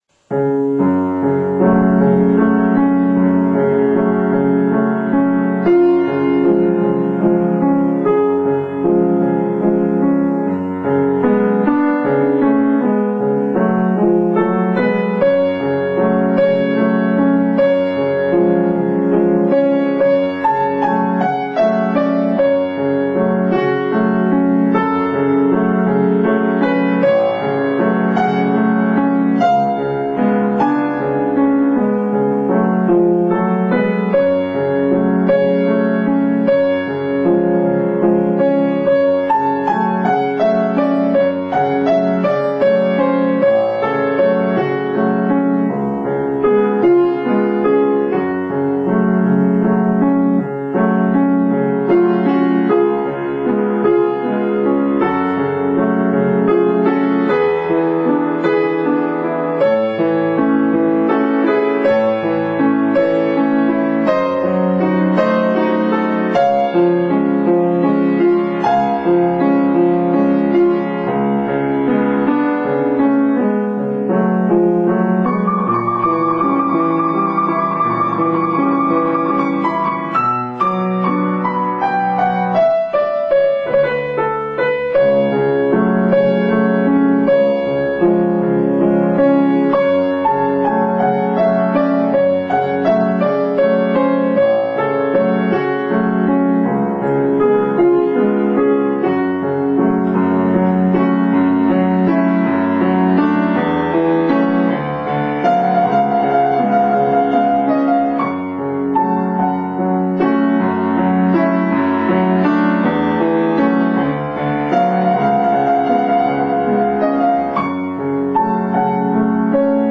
ピアノのしらべ：メンデルスゾーン作曲「ベニスの舟歌」